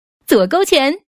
left-hook.mp3